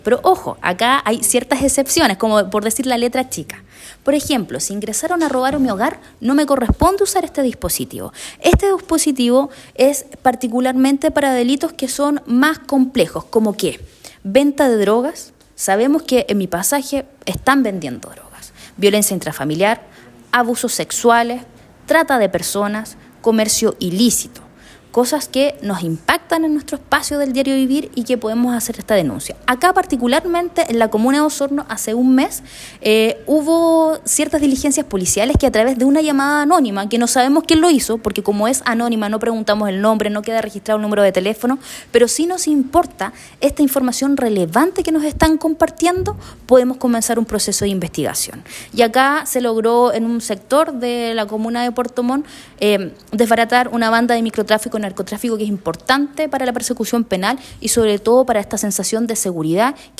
Paulina Muñoz, ex representante de la Subsecretaría de Prevención del Delito y actual Delegada Presidencial Regional, destacó la importancia de este sistema en la construcción de comunidades más seguras.